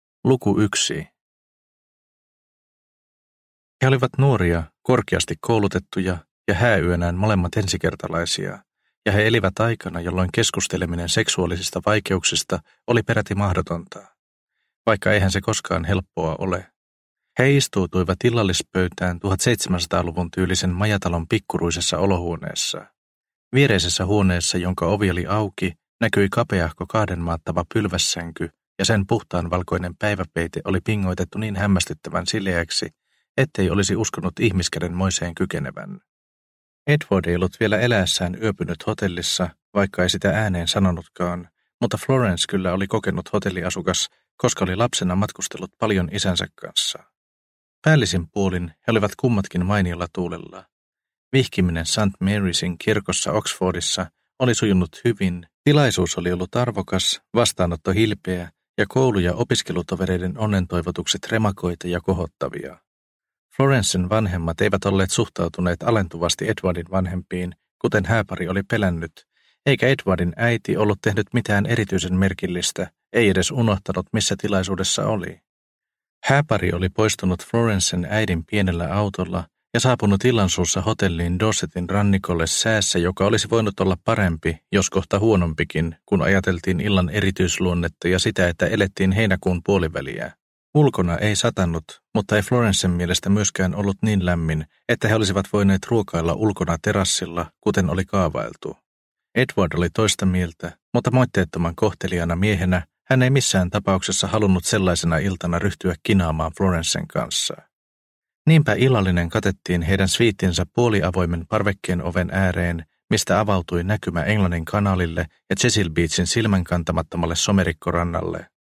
Rannalla – Ljudbok – Laddas ner